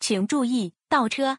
reverse_gear.wav